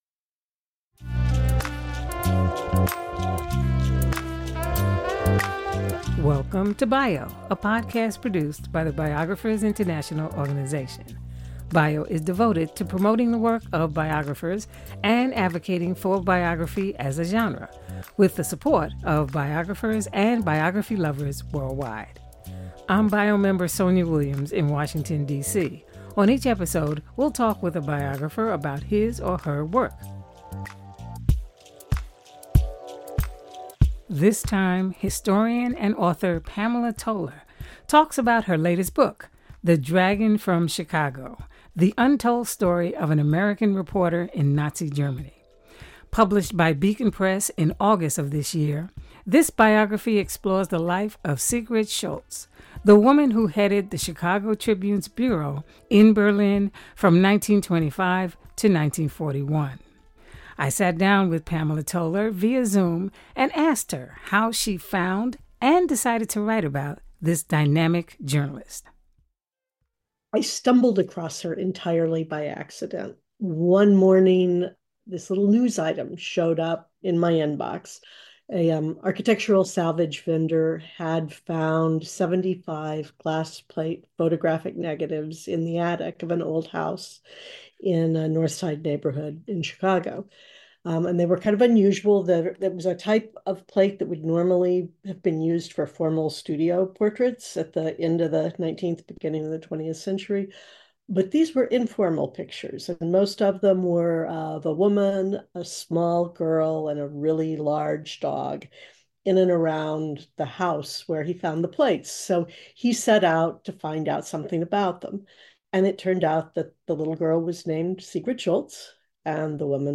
Welcome! Each week, we post fascinating discussions with biographers from around the country and the world.